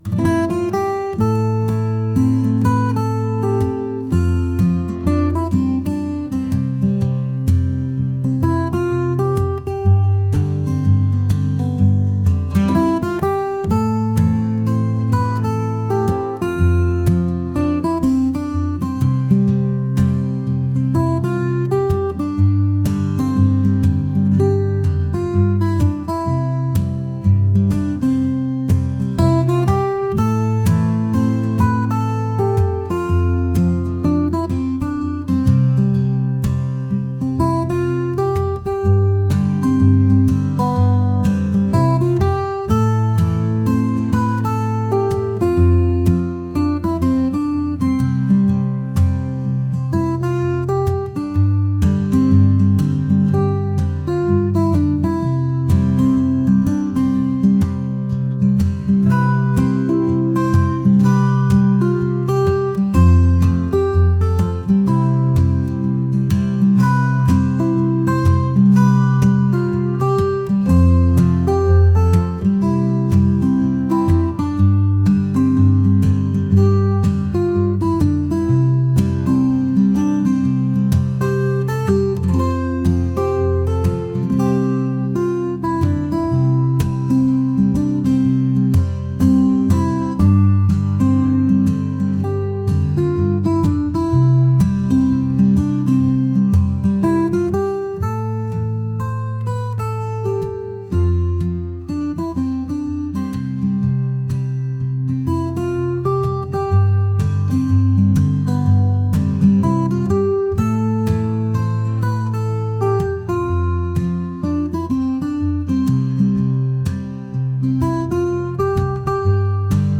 folk | soulful | acoustic